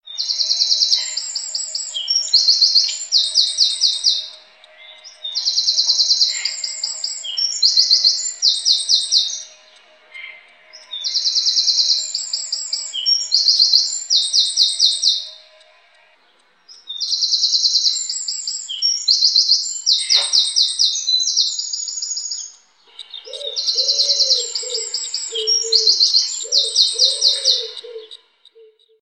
جلوه های صوتی
دانلود صدای چکاوک از ساعد نیوز با لینک مستقیم و کیفیت بالا